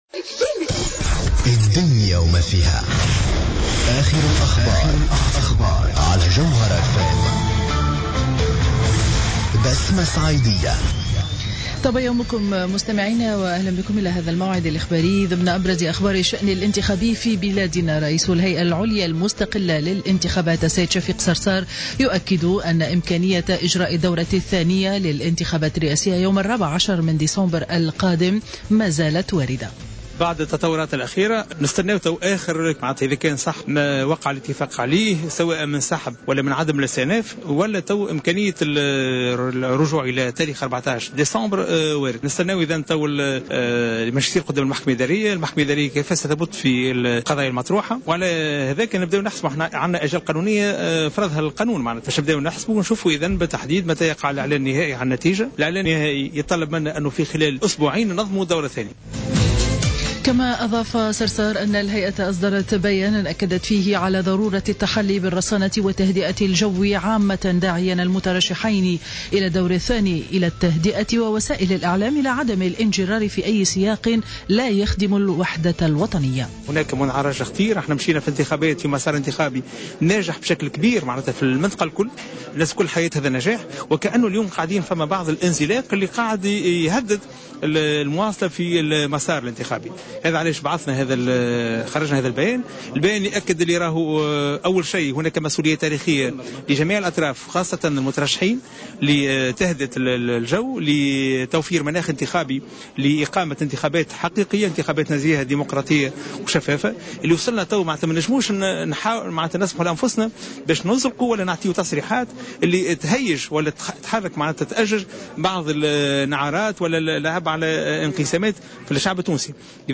نشرة أخبار السابعة صباحا ليوم 30-11-14